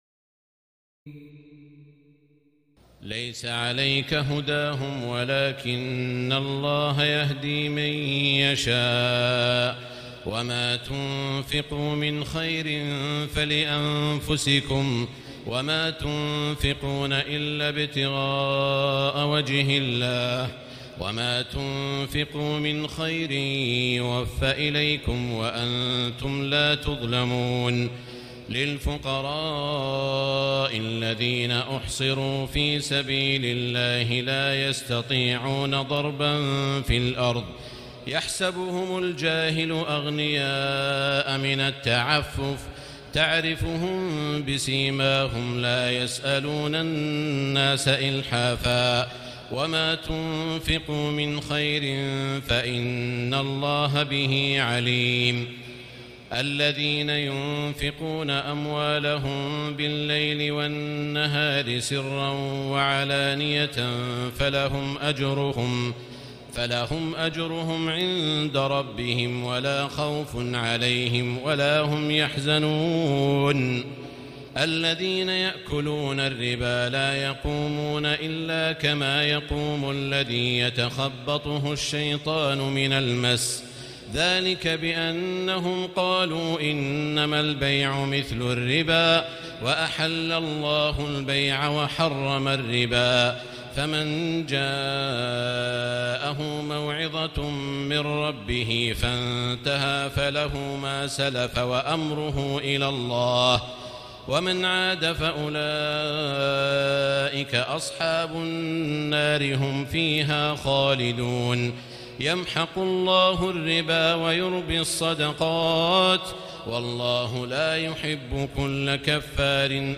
تراويح الليلة الثالثة رمضان 1439هـ من سورتي البقرة (272-286) و آل عمران (1-63) Taraweeh 3st night Ramadan 1439H from Surah Al-Baqara and Surah Aal-i-Imraan > تراويح الحرم المكي عام 1439 🕋 > التراويح - تلاوات الحرمين